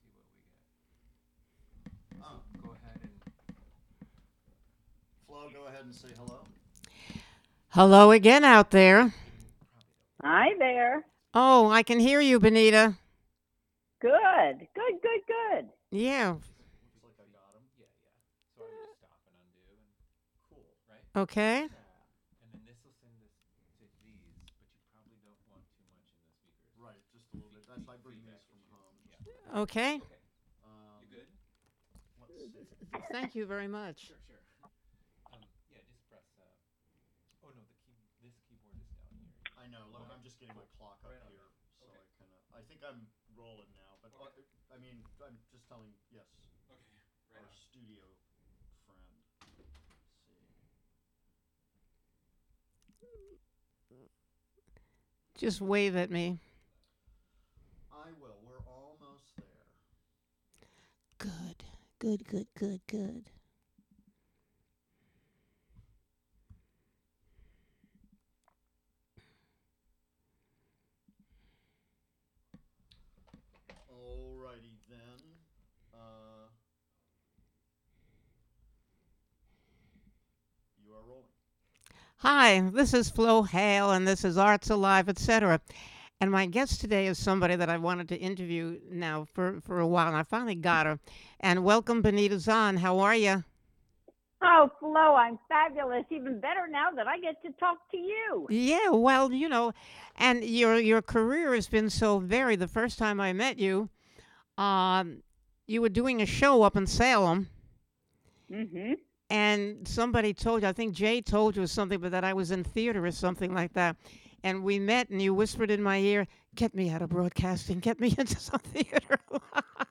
With a wicked wit and a true heart she banters with fellow singers and thespians, local luminaries and mover/shakers and knows how to get them to reveal what makes them tick.